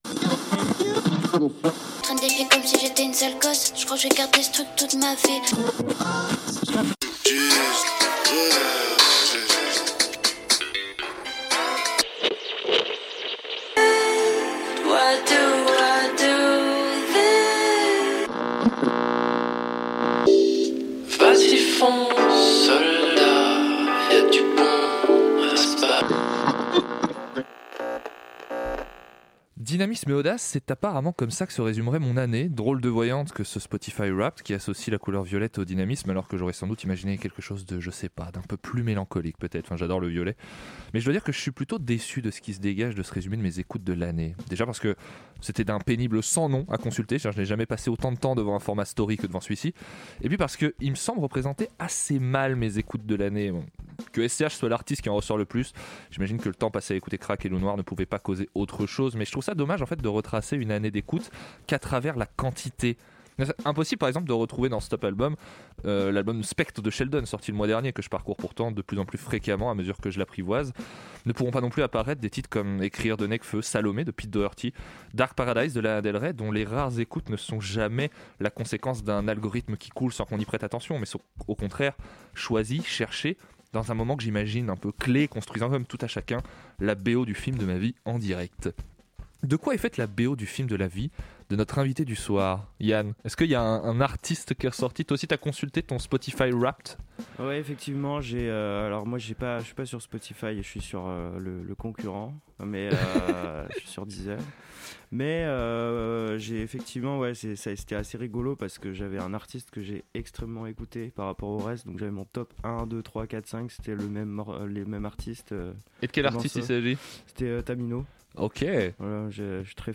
Cette saison, la Fraîche Liste met à l'honneur les artistes qu'elle diffuse dans une émission mensuelle faite de découvertes, de confessions et de musique. Les trois programmateurs.rices vous invitent à faire plus ample connaissance avec l'une des voix qui les a sédui.te.s ce mois-ci, et à découvrir la sélection mensuelle plus en profondeur.